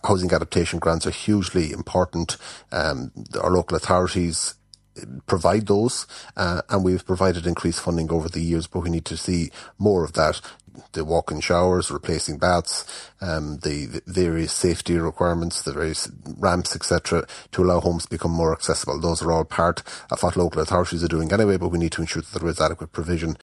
Senator Seán Kyne, Fine Gael’s Seanad leader, says they want to see what’s in the Programme for Government become a reality…………